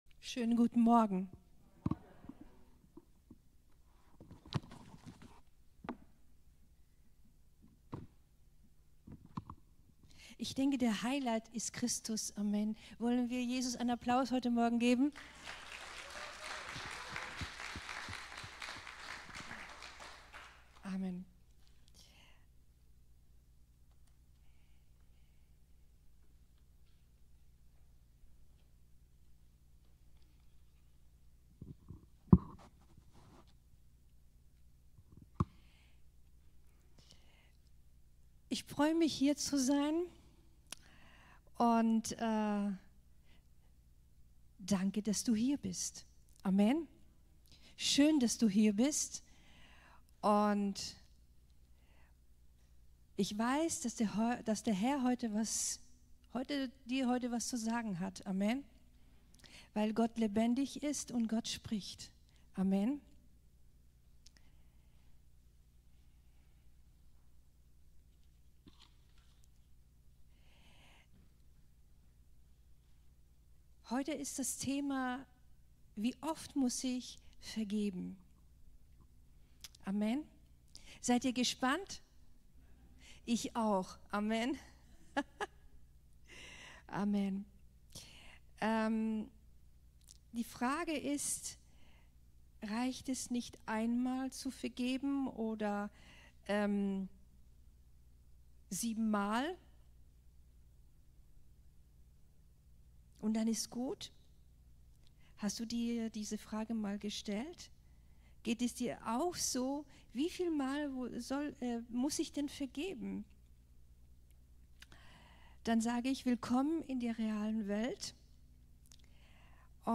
19.10.2025 Ort: Gospelhouse Kehl